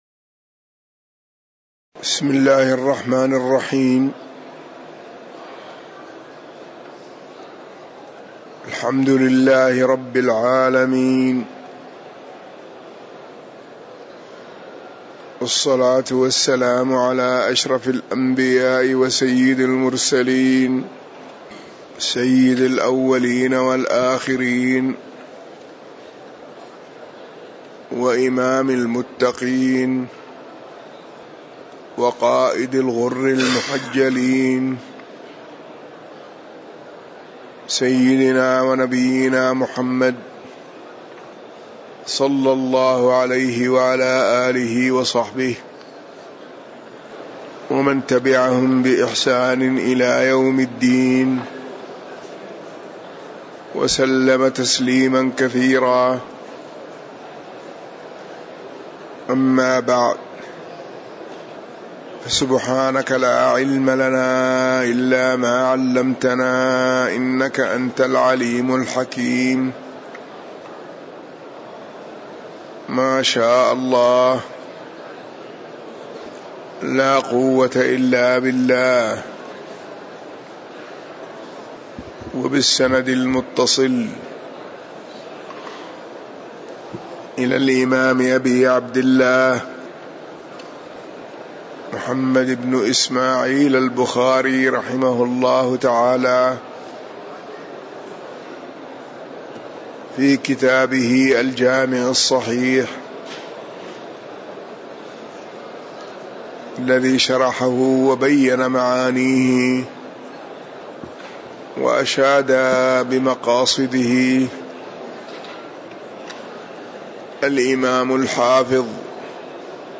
تاريخ النشر ٢٤ صفر ١٤٤١ هـ المكان: المسجد النبوي الشيخ